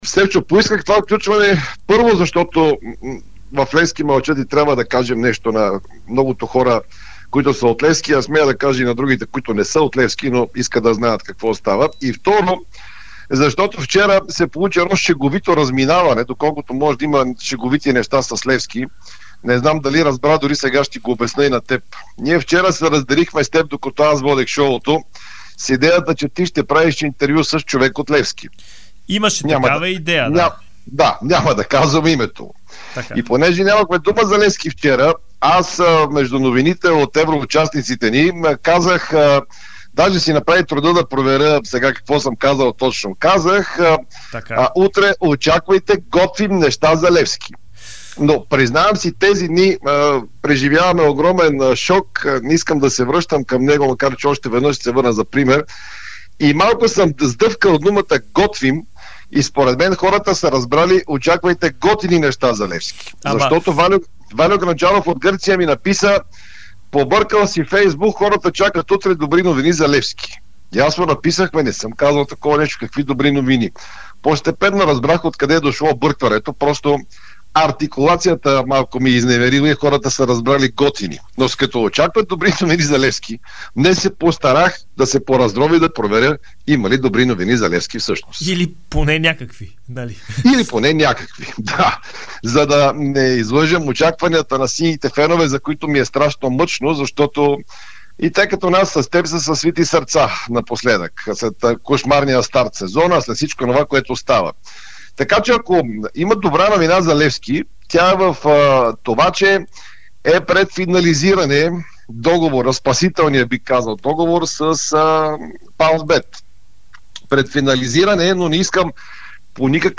Предлагаме ви тяхната дискусия в прикачения аудио файл.